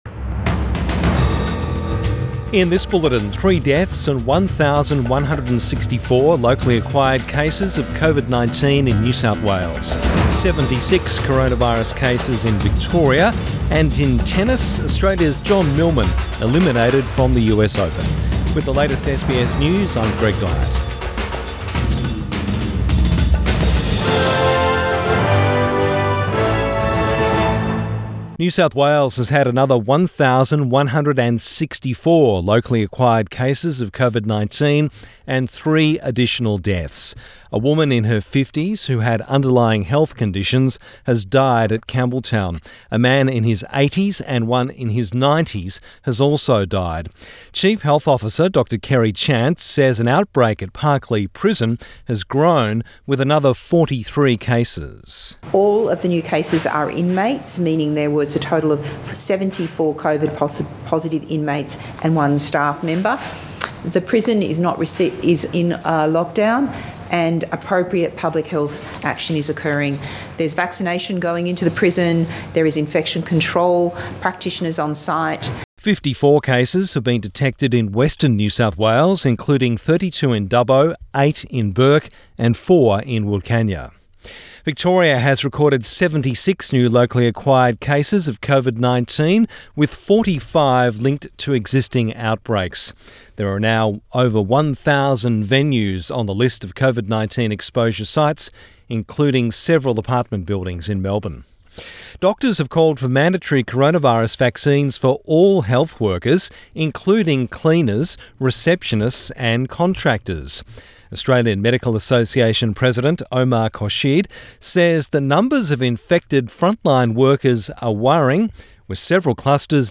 Midday bulletin 31 August 2021